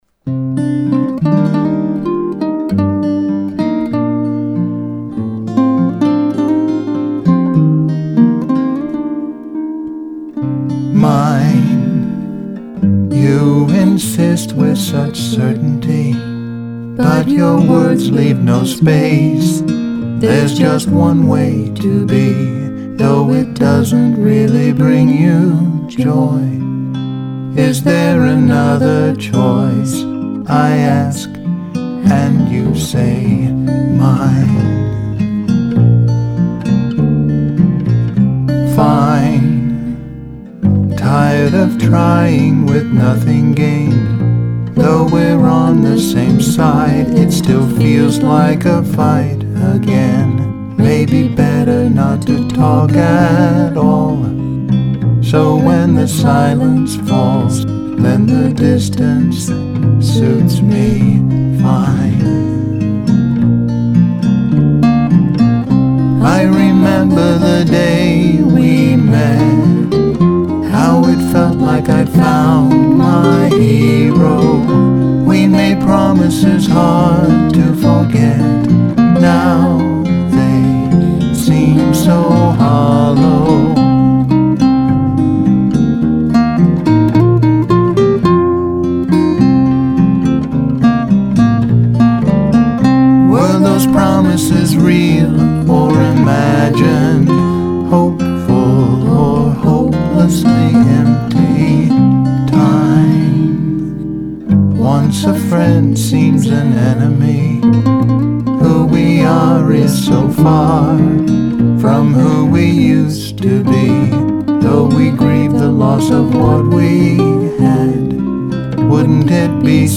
haunting sound
guitar